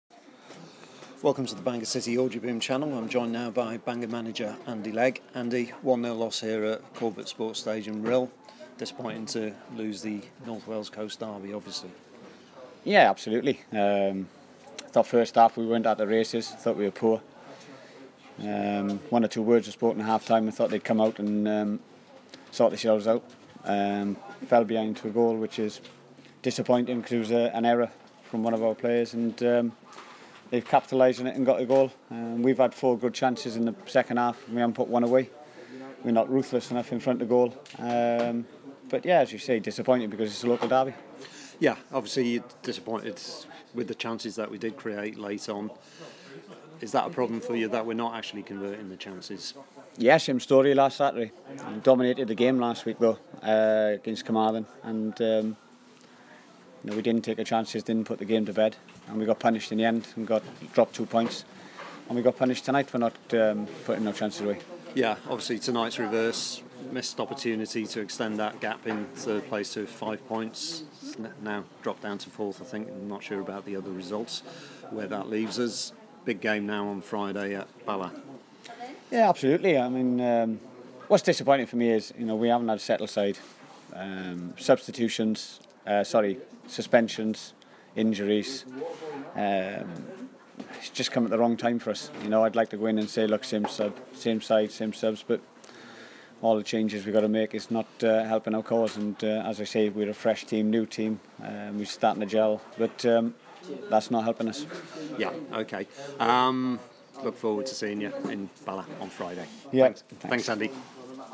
Citizens Interview